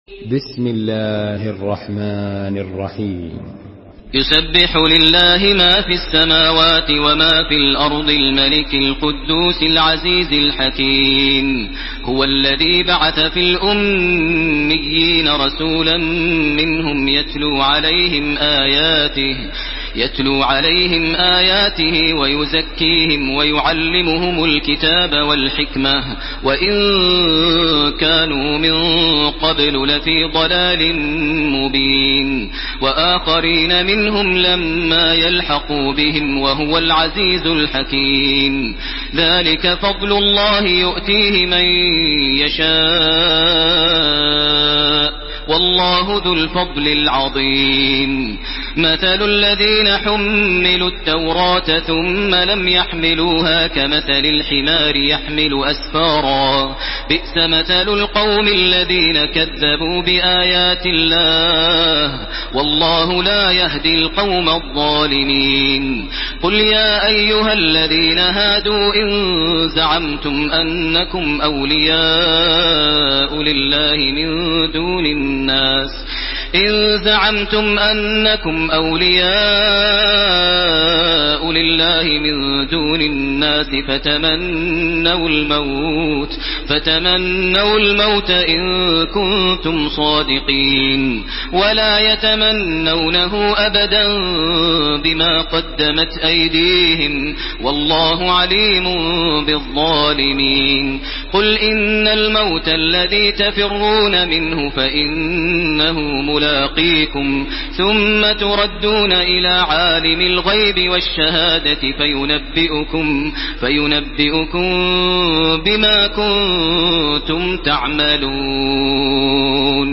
تحميل سورة الجمعة بصوت تراويح الحرم المكي 1431
مرتل